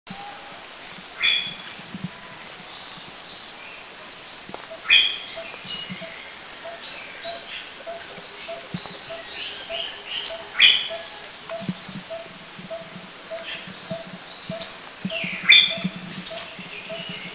Tawny Grassbird
Megalurus timoriensis
TawnyGrassbird.mp3